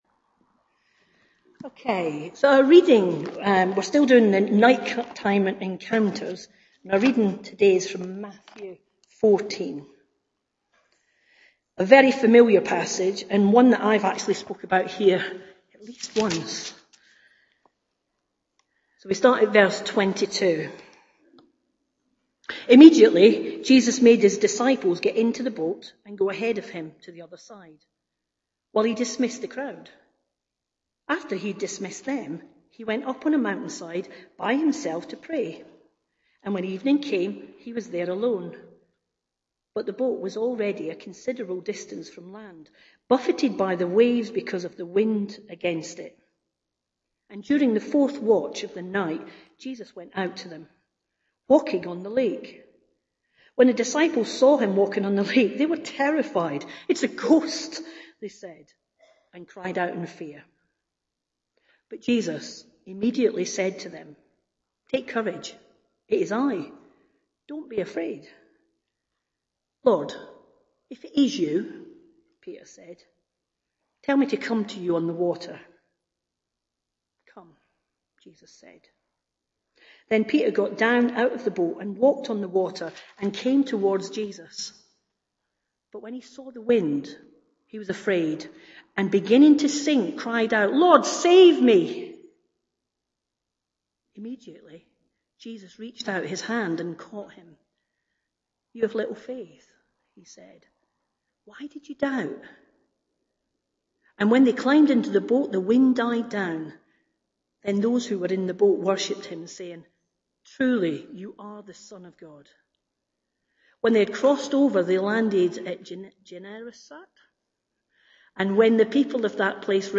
Jul 14, 2019 Night-time Encounters part 5 MP3 SUBSCRIBE on iTunes(Podcast) Notes Discussion Sermons in this Series Reading - Matthew 14:22-36 We apologise for poor sound quality Loading Discusson...